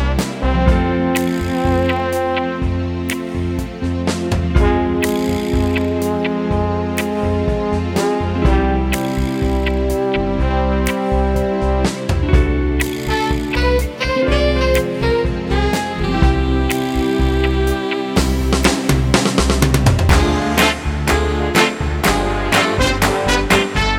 Version 2 Crooners 3:22 Buy £1.50